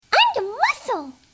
I've also done Kiki sound bites (those of my friends who've read the strip --still trying to recruit more--